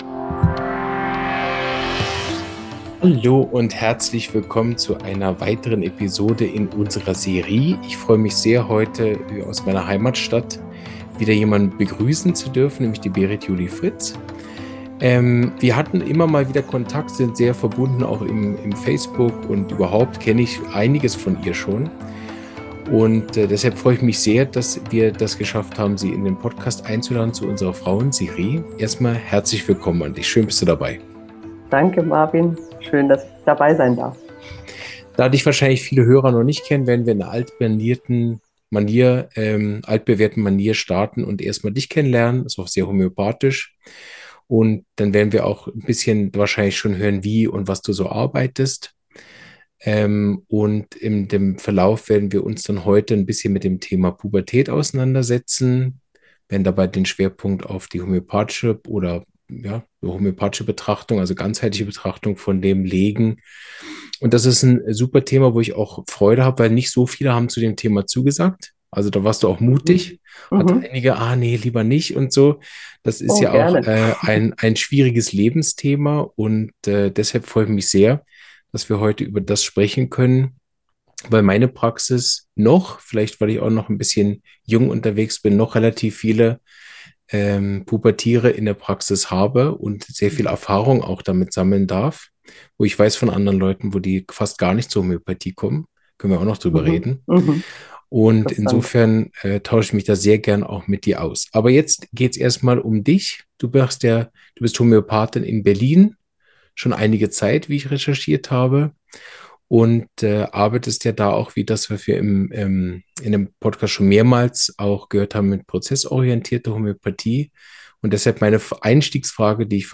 Dabei beleuchten wir, wie systemische Ansätze und prozessorientierte Homöopathie Jugendlichen und ihren Familien neue Perspektiven bieten. Wir diskutieren gesellschaftliche Erwartungen, Leistungsdruck, Geschlechterrollen und die Bedeutung individueller Begleitung. Freu dich auf ein intensives, ehrliches Gespräch voller Impulse für Eltern, Therapeuten und alle, die Pubertät neu verstehen wollen.